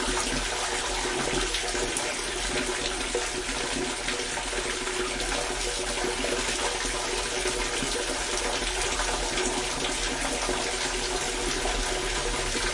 随机 " 下水道关闭1
Tag: 漏极 下水道 关闭